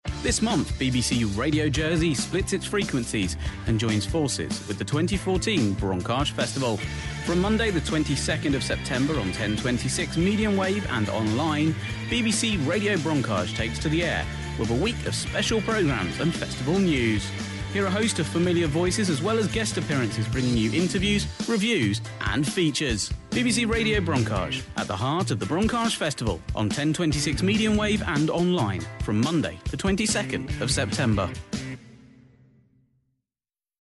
BBC Radio Branchage is a pop up radio station run by BBC Radio Jersey.